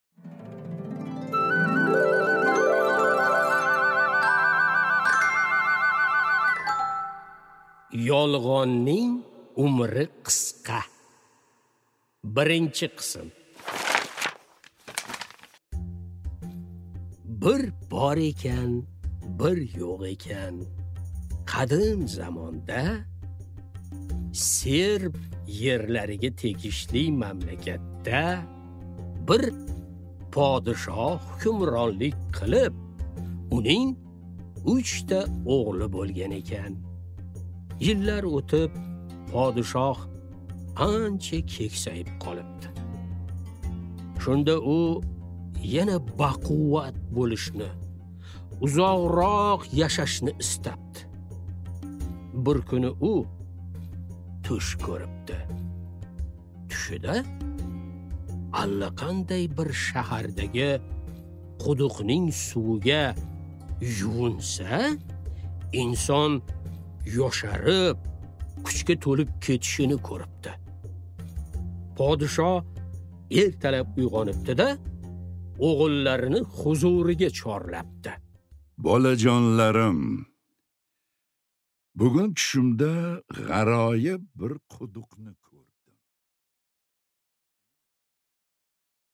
Аудиокнига Yolg'onning umri qisqa